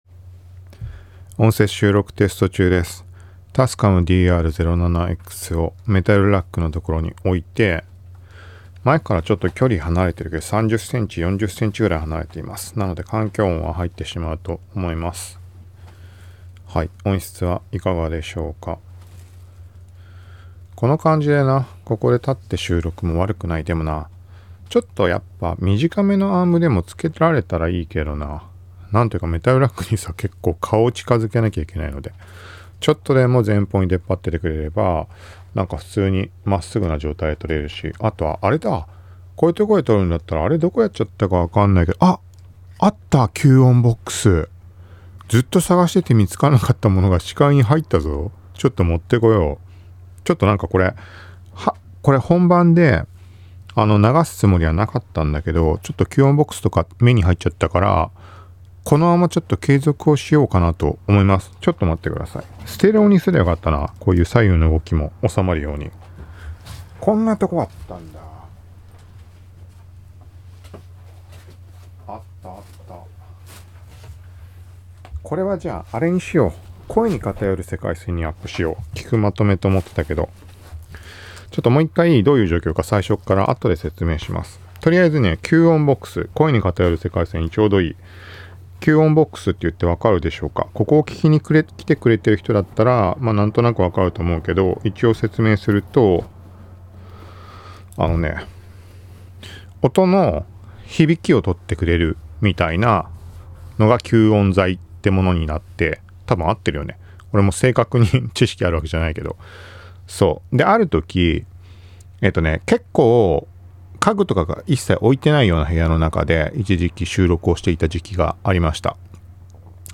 troystudio-kyuon-box-for-mic-recording-podcast-tascam-dr-07x.m4a